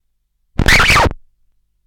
Record Scratch #3
comedy crackle effect lp needle noise phonograph record sound effect free sound royalty free Funny